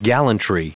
Prononciation du mot gallantry en anglais (fichier audio)